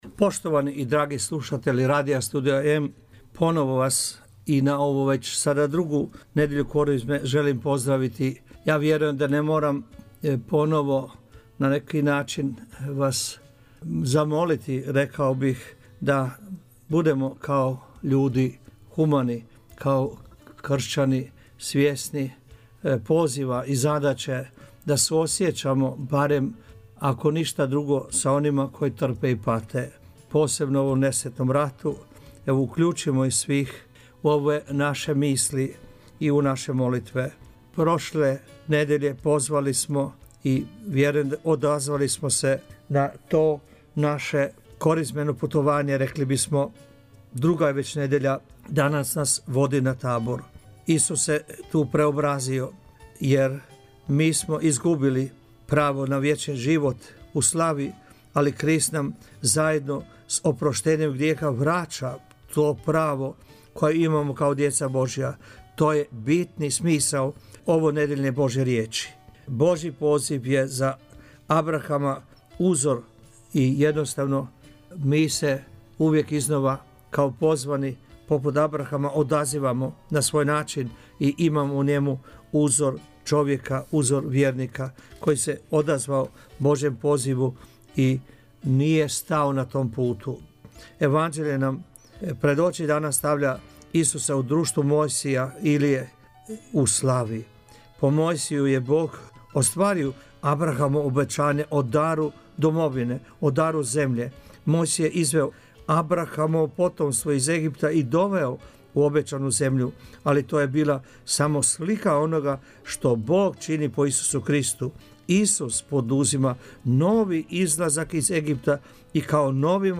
propovjed